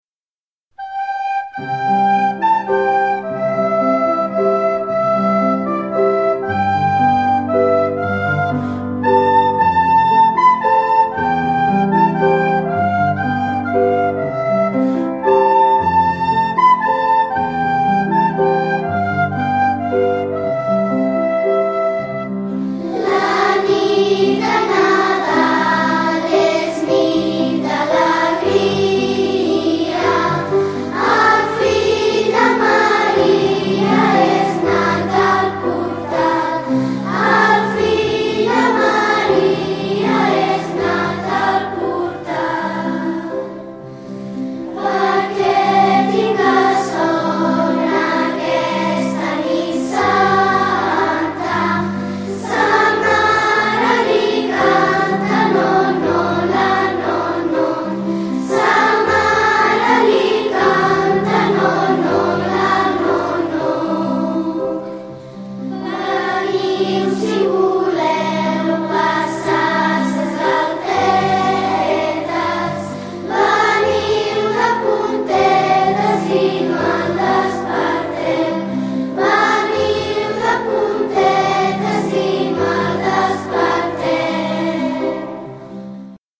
és una cançó molt dolça que serveix per fer dormir els nadons